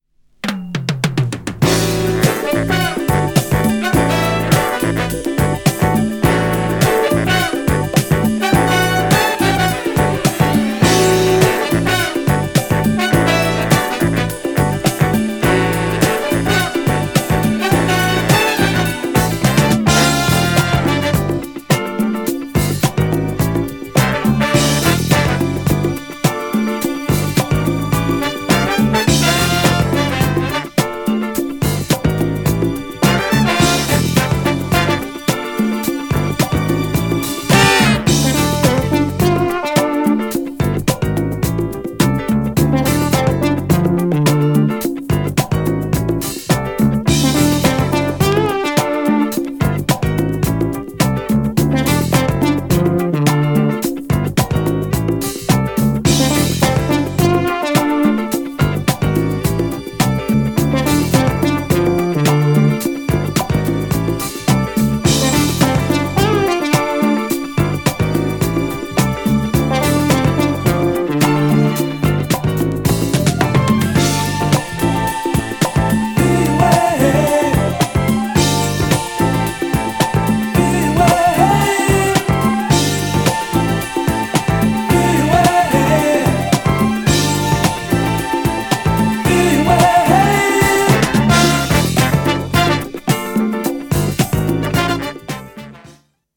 FUSION FUNKなB面も最高!!
GENRE Dance Classic
BPM 96〜100BPM